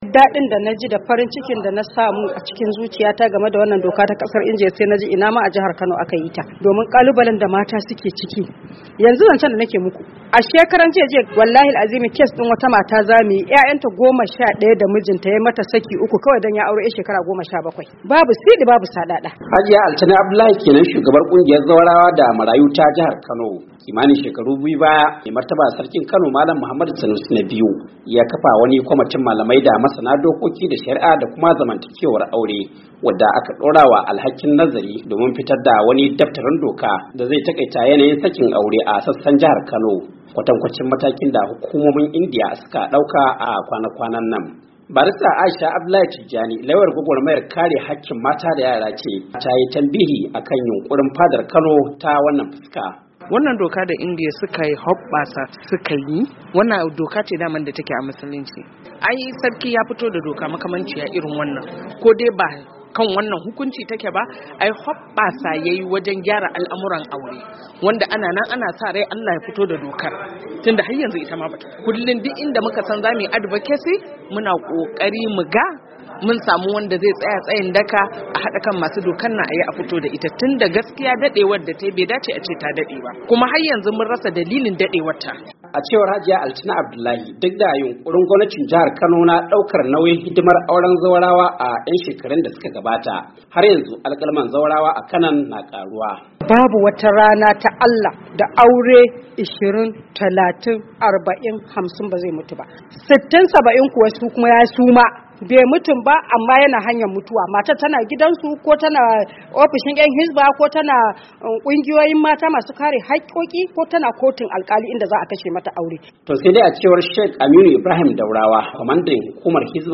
cikakken rahoto akan hakan...